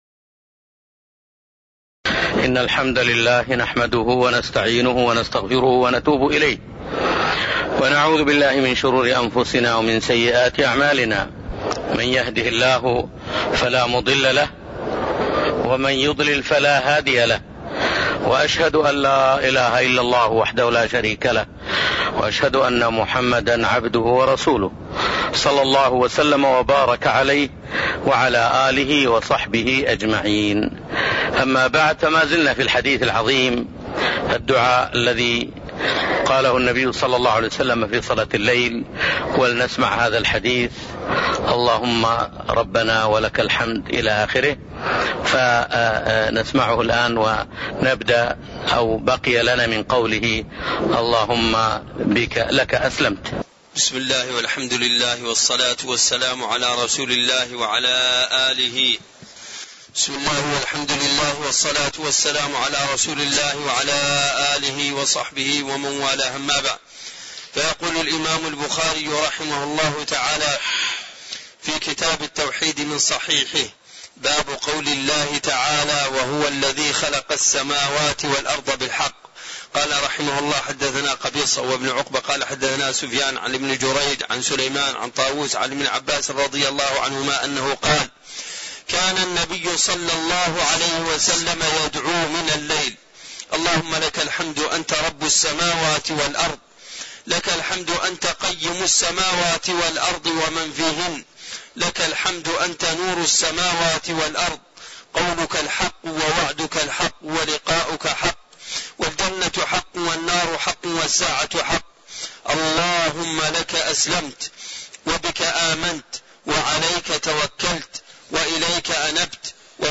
تاريخ النشر ٢٠ صفر ١٤٣٣ هـ المكان: المسجد النبوي الشيخ